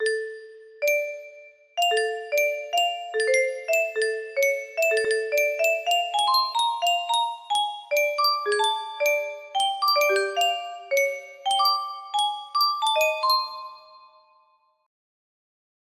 idk I'm just experamenting music box melody